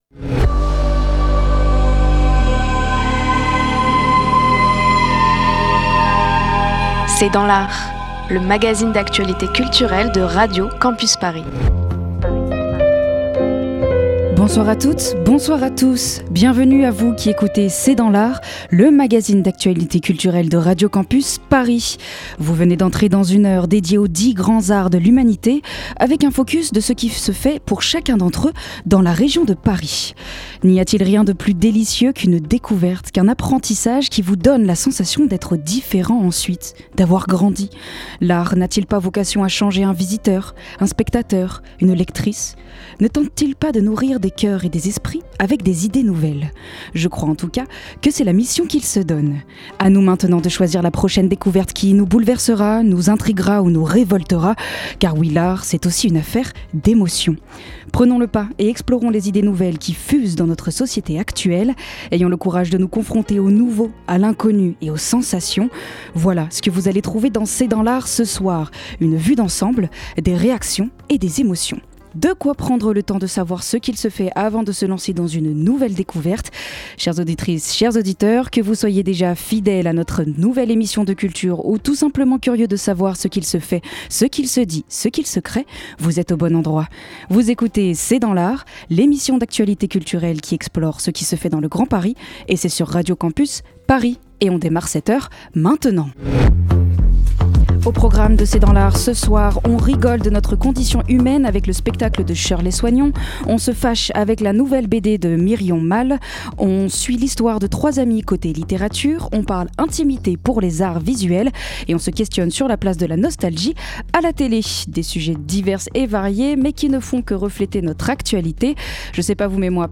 Magazine Culture